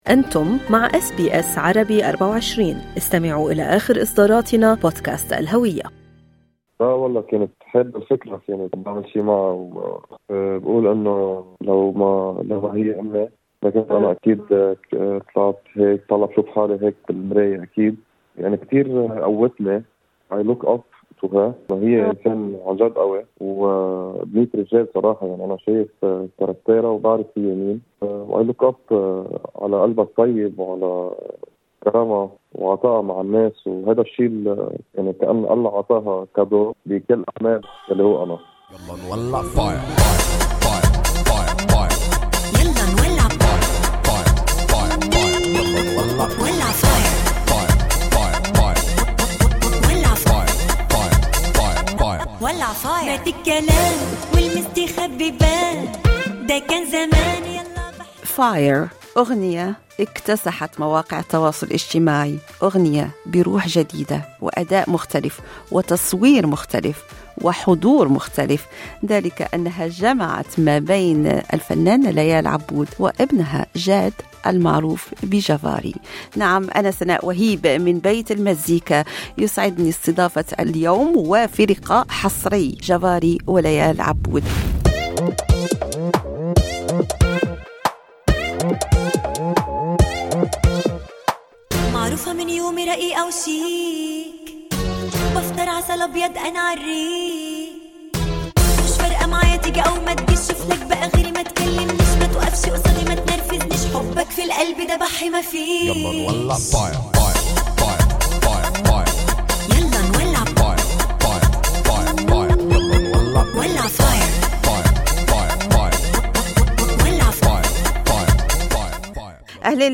المزيد في اللقاء الوارد أعلاه